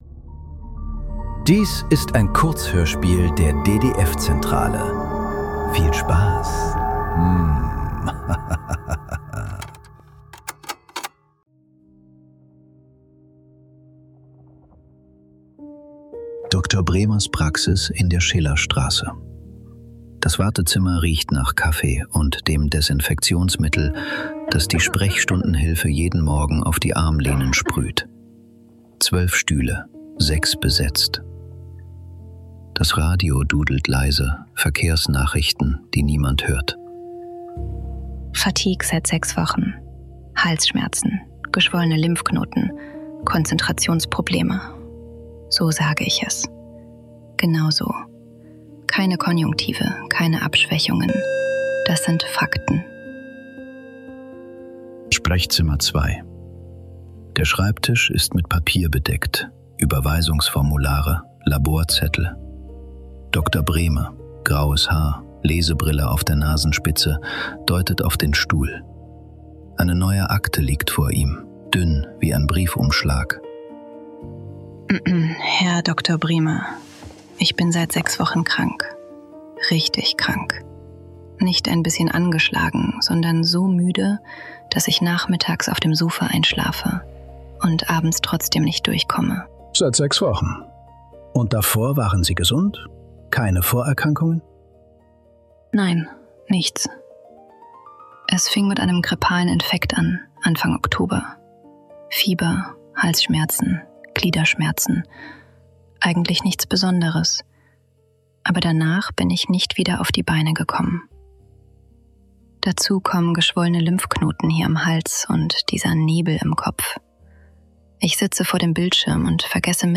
Befund: Unauffällig ~ Nachklang. Kurzhörspiele.